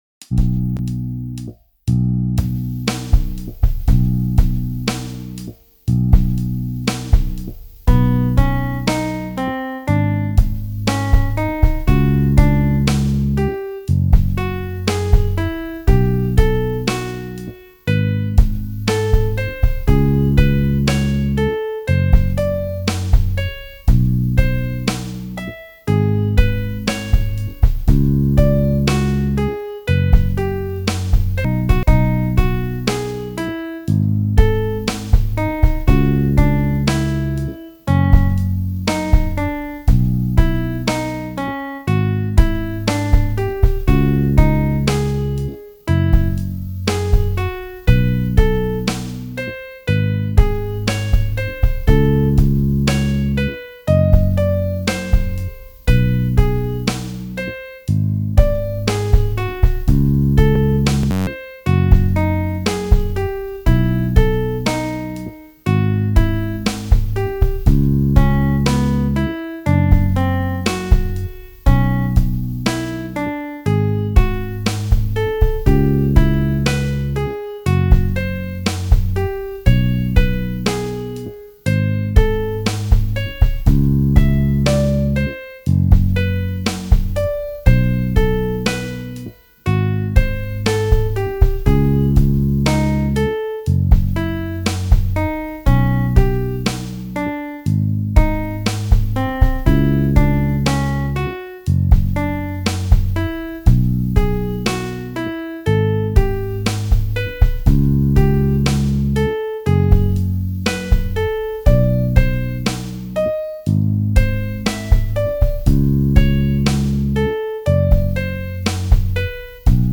B Major Pentatonic Slow Tempo